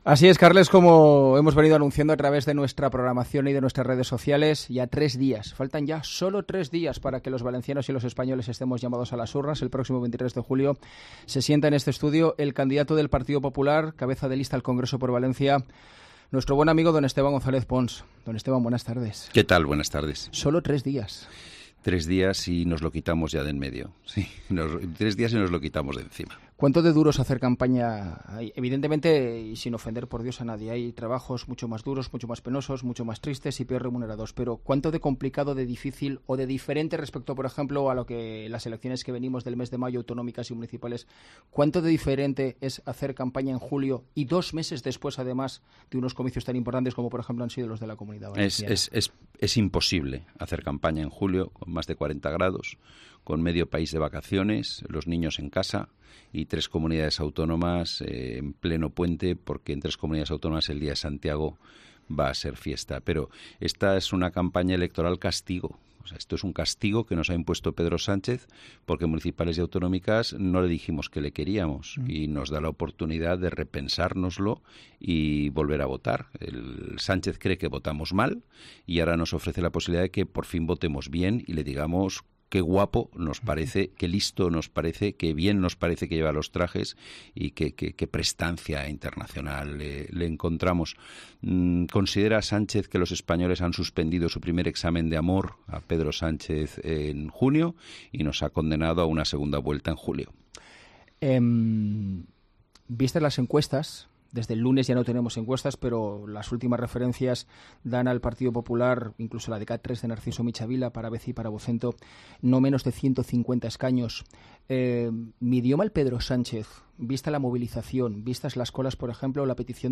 Entrevista completa con Esteban González Pons